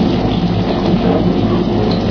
techage_reactor.ogg